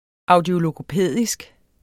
Udtale [ ˈɑwdjologopεˀðisg ] eller [ ɑwdjologoˈpεˀðisg ]